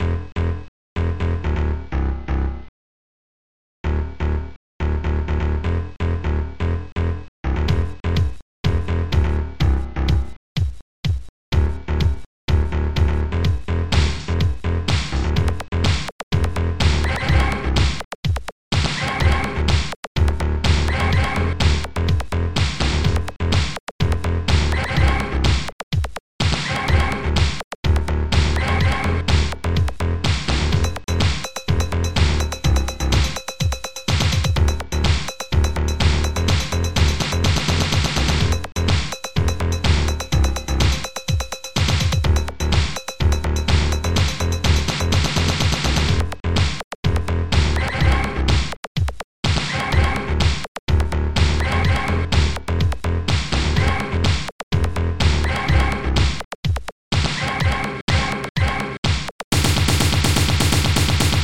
st-02:housebass st-02:jacktit st-02:petblast st-02:subbass st-02:snapshot st-02:snare1